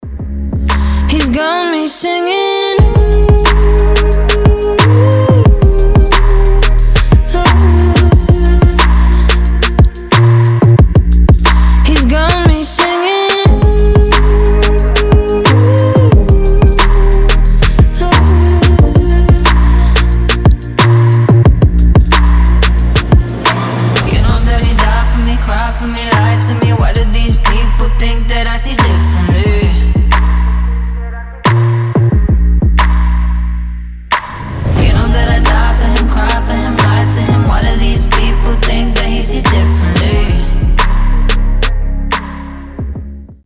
женский вокал
Trap
Chill Trap
Bass